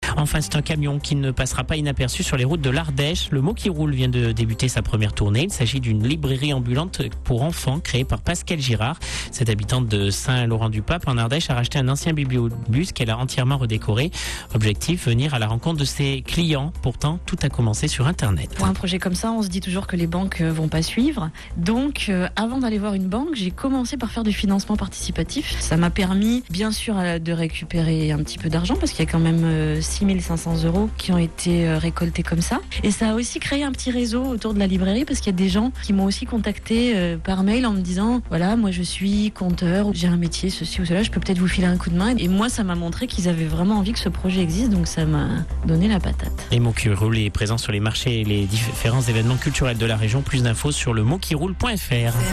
3 petits passages sur l'antenne de Chérie FM Drôme-Ardèche le 27 octobre.